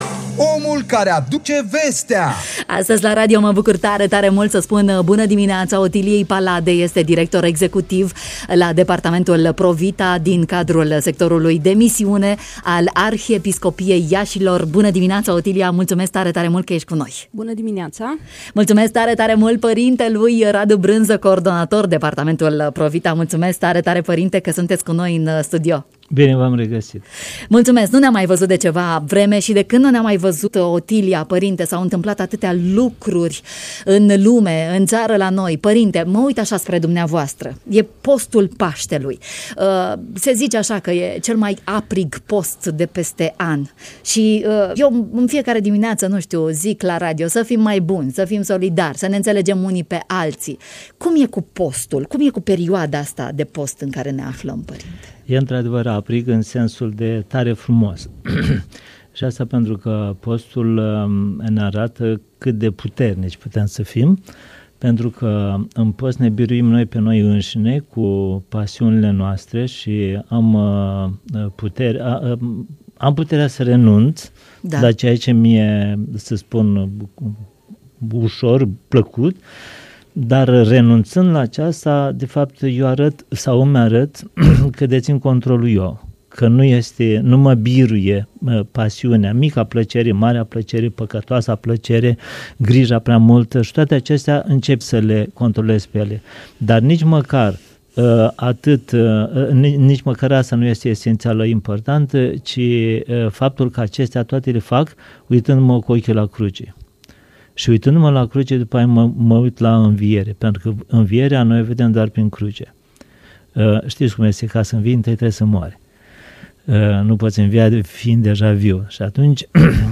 în matinal